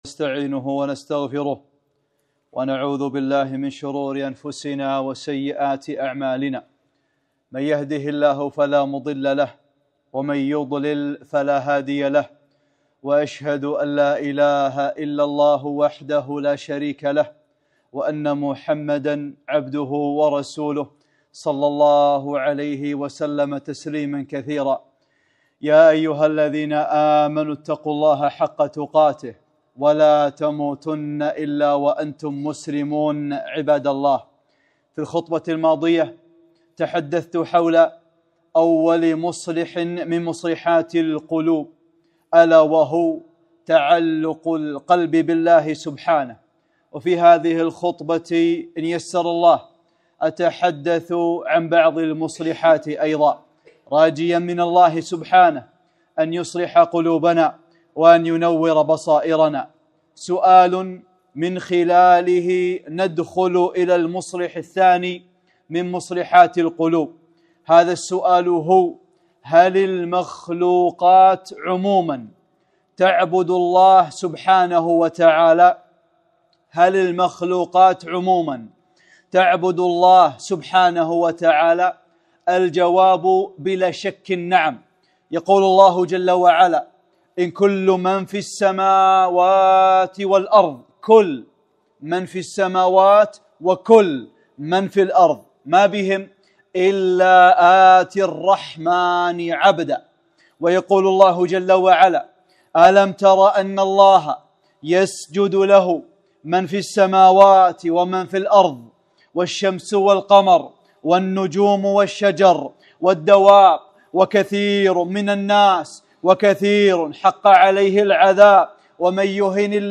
خطبة - (3) مُصلحات القلوب - أعمال القلوب - دروس الكويت